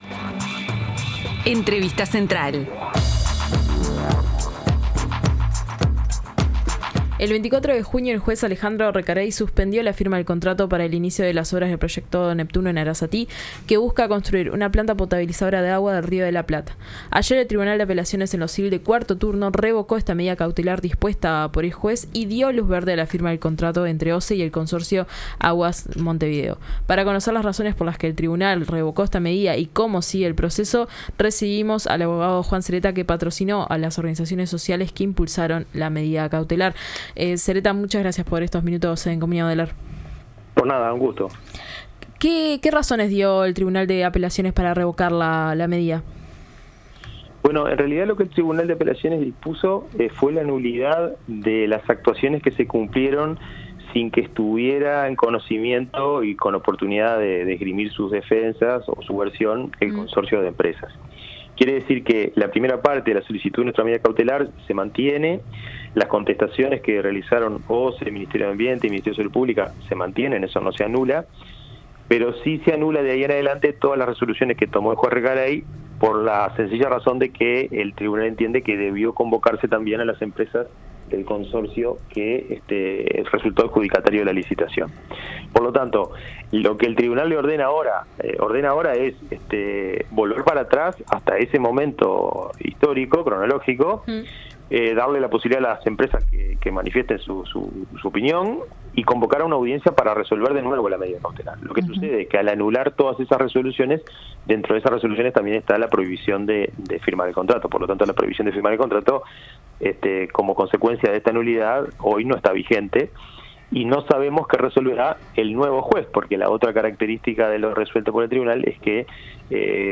En las elecciones nacionales el Partido Colorado obtuvo 392.592 votos, lo que le permitió acceder a 17 bancas en la Cámara de Representantes, 4 más de las que obtuvo en 2019 para el período legislativo 2020-2025, y 5 en la Cámara de Senadores. A 3 días del balotaje, en el marco del Ciclo Legislativo, recibimos a Carlos Rydstrom, ex Director General de Desarrollo Rural en el Ministerio de Ganadería, Agricultura y Pesca y diputado electo por el Partido Colorado para este período de gobierno.